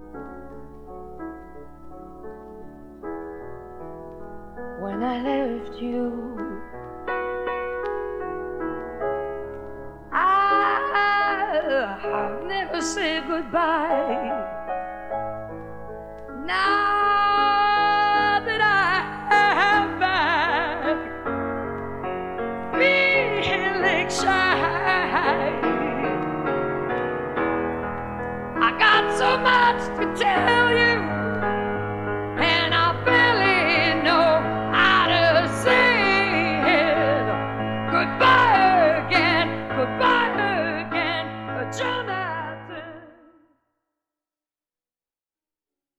Recorded: Olympic Sound Studio in Barnes / London, England